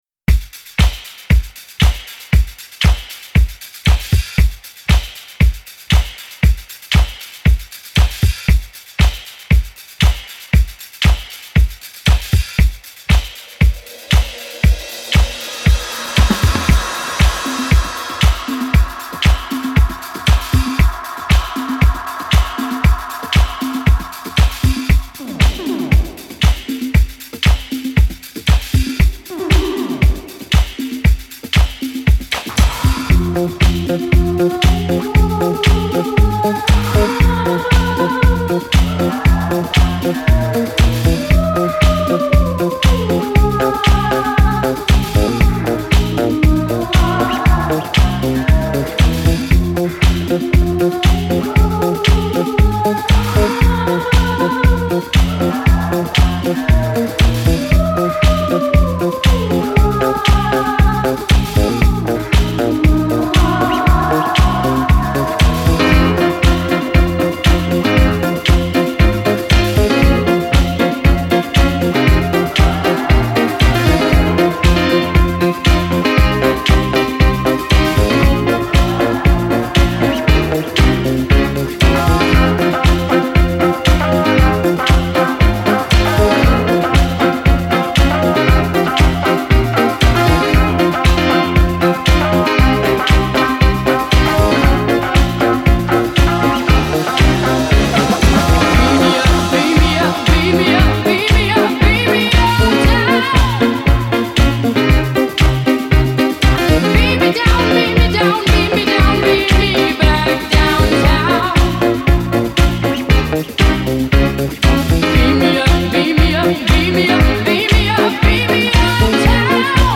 This remix is pure disco magic.